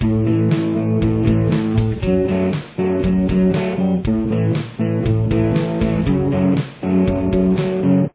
Here it is playing just the guitars :